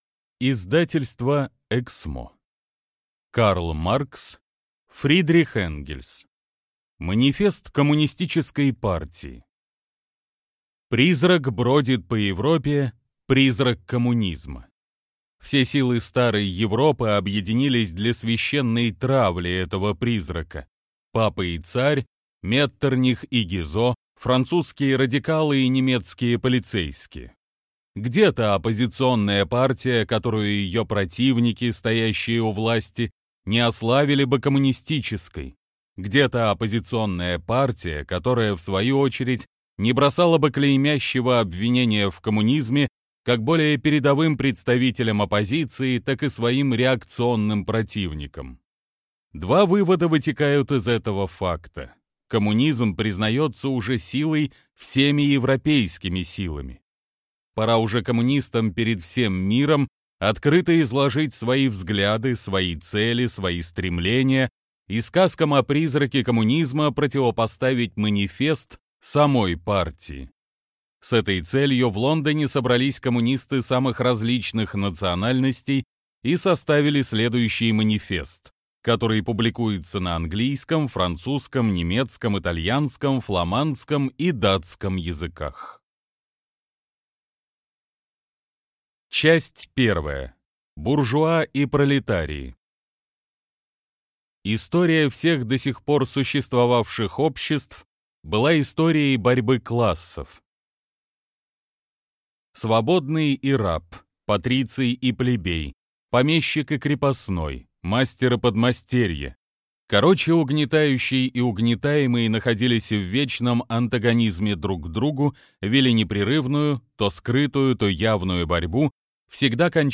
Аудиокнига Манифест Коммунистической партии | Библиотека аудиокниг